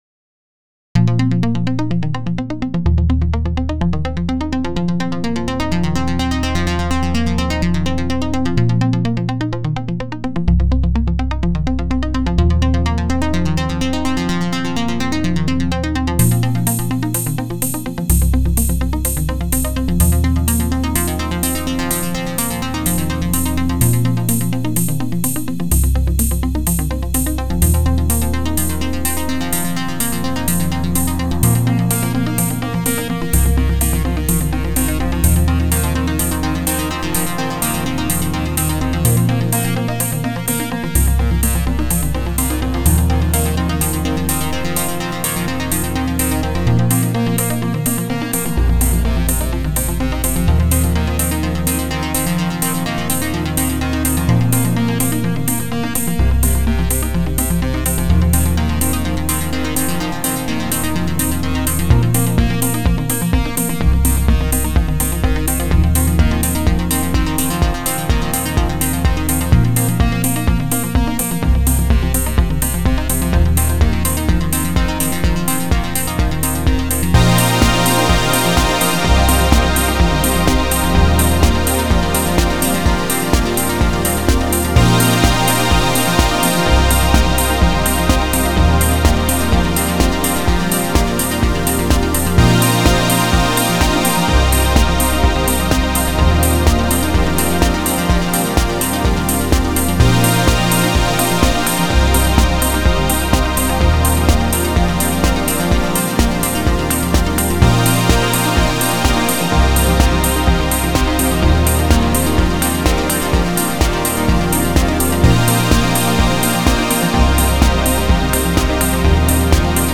cover track